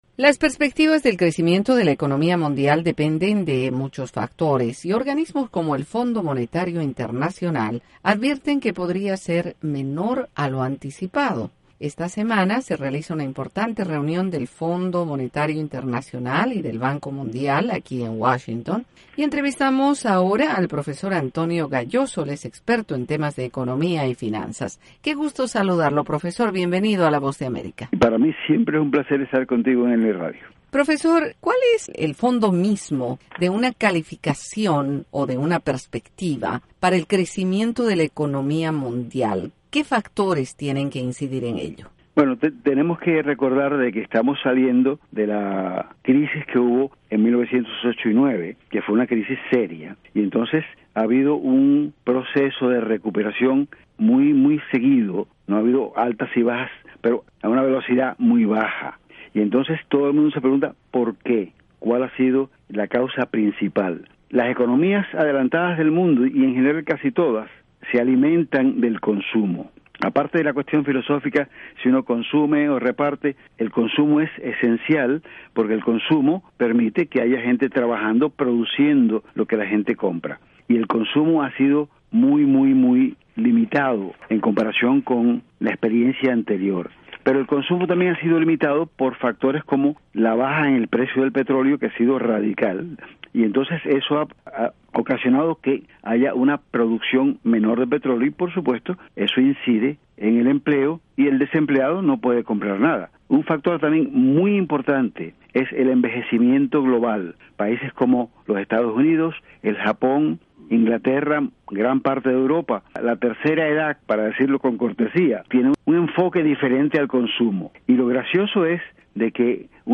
Entrevista con el experto en economía y finanzas